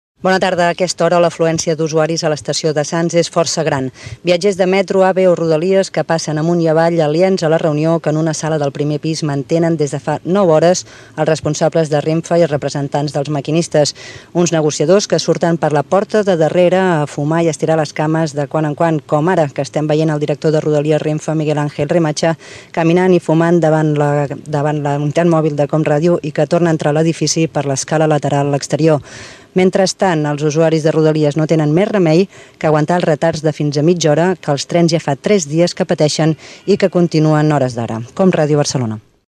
Connexió amb la unitat mòbil per informar de les negociacions per aturar la vaga dels maquinistes de Renfe a l'estació de Sants de Barcelona
Informatiu